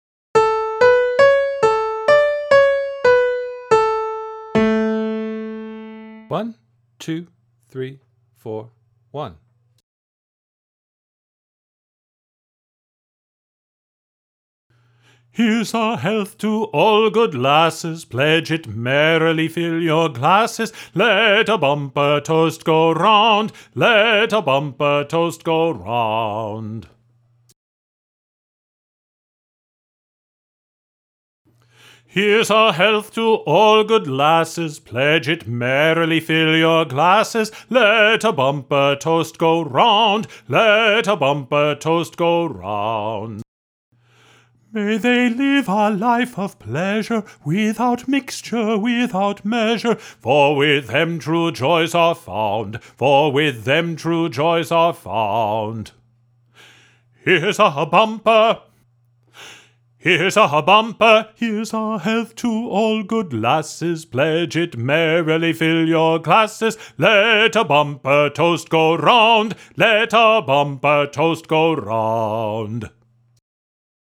A Celebrated Glee!
Bass Audio Part
Heres-A-Health-to-All-Good-Lasses_baritone.mp3